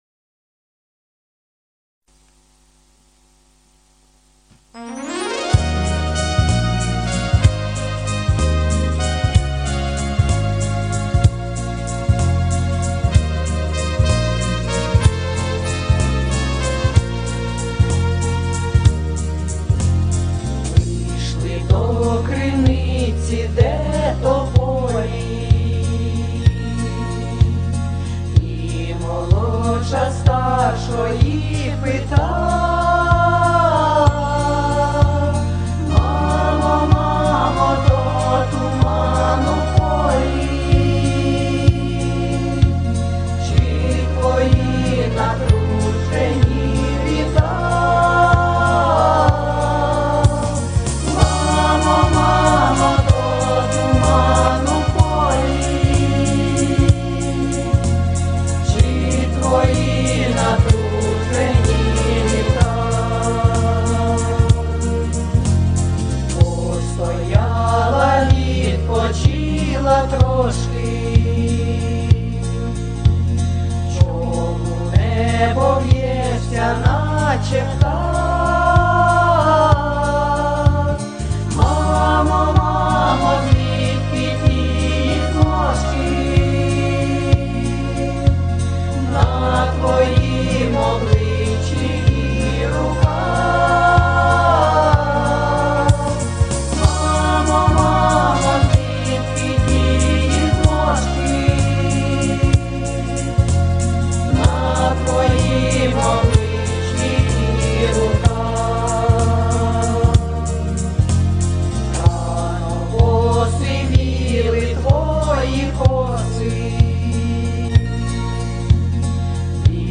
Всі мінусовки жанру Pop-Folk
Плюсовий запис